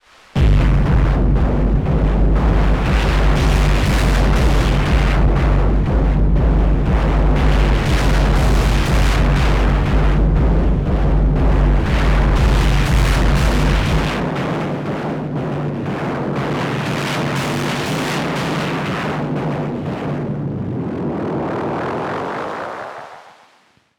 two little somethings, Digitakt with Wavetables and my DFAM samples …
(based on the first AH +FX preset, towards the end i use bass focus to remove the low end, so i could add a kick from the Syntakt or A4 for example)